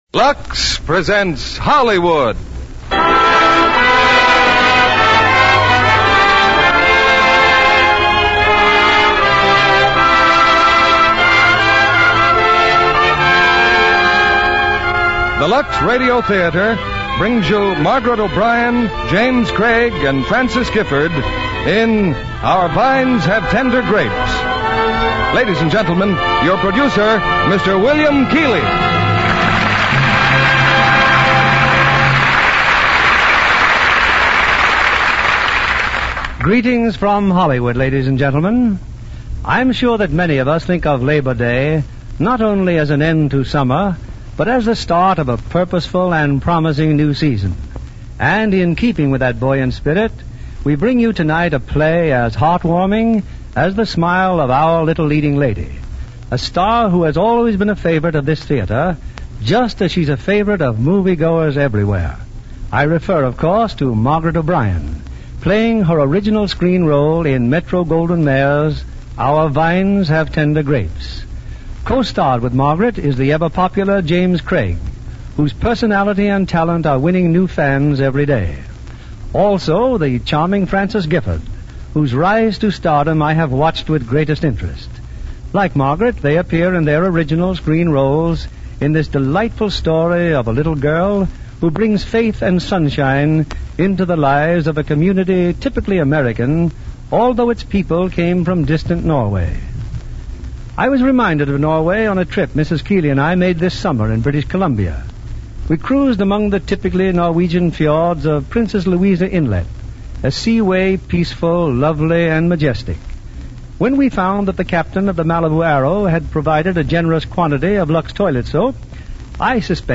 Our Vines Have Tender Grapes, starring Margaret O'Brien, James Craig, Frances Gifford